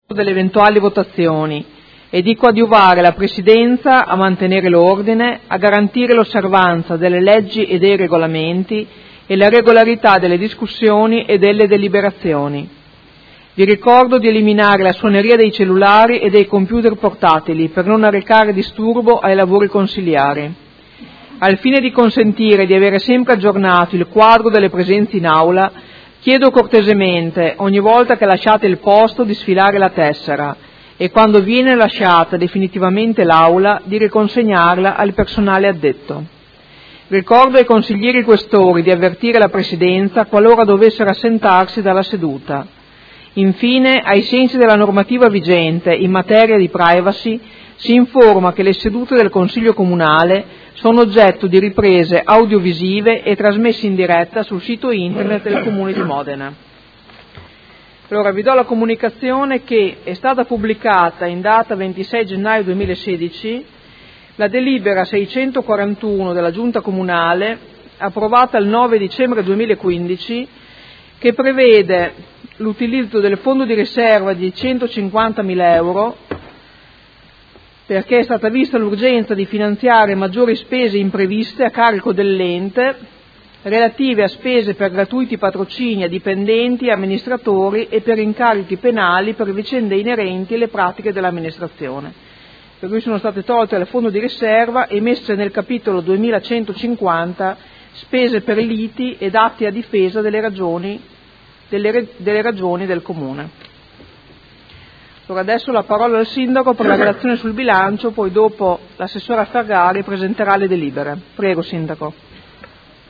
Presidente — Sito Audio Consiglio Comunale
Seduta del 28 gennaio. apertura del Consiglio Comunale